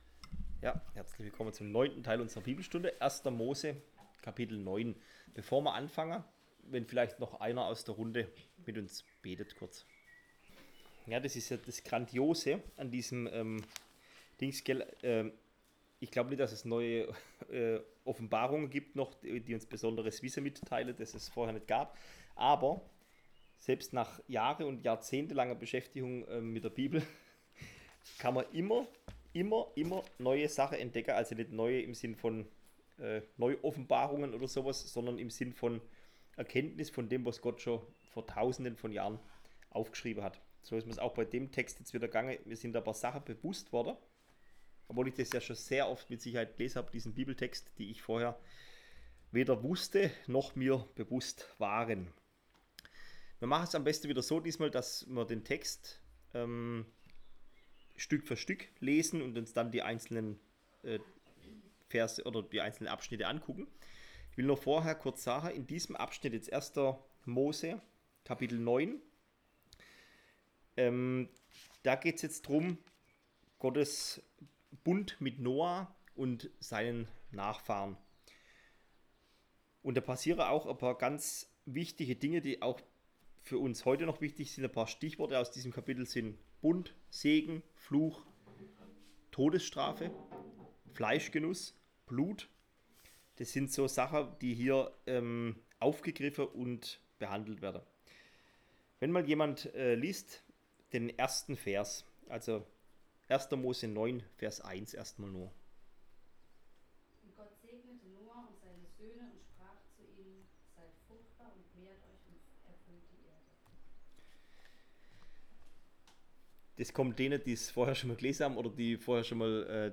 Bibelstunde – Baptisten Mergentheim
Bibelstunde_1Mo9.mp3